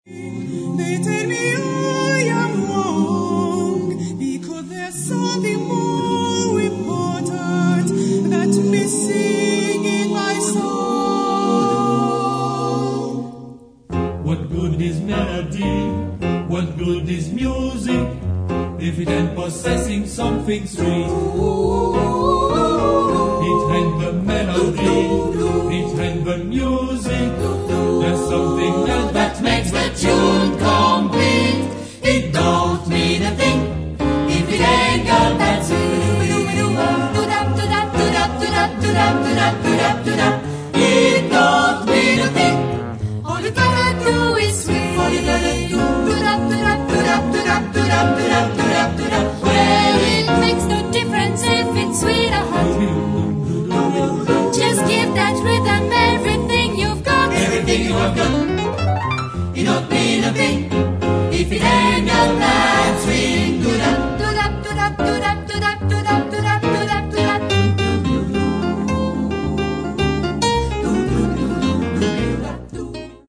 SATB (4 voix mixtes) ; Partition complète.
Jazz vocal. Standards de jazz.
swing ; vivant ; syncopé ; dansant
Solistes : Soprano (1)
Tonalité : fa mineur